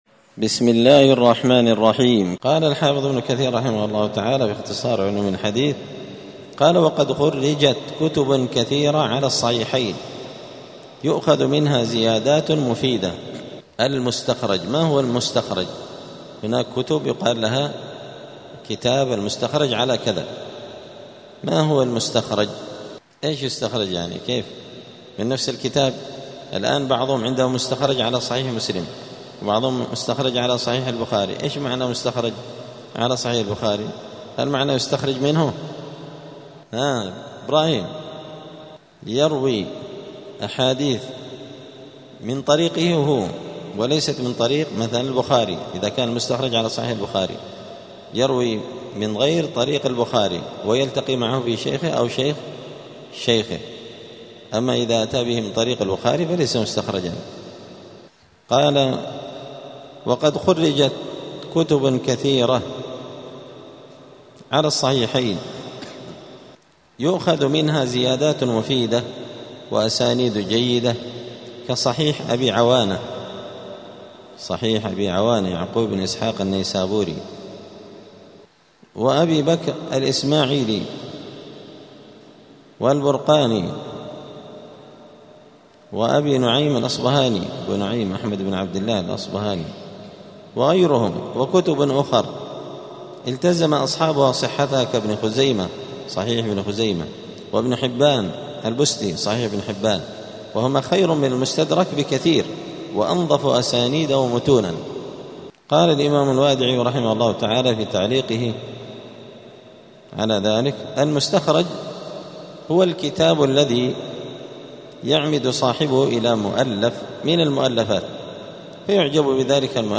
دار الحديث السلفية بمسجد الفرقان قشن المهرة اليمن
8الدرس-الثامن-من-السير-الحثيث.mp3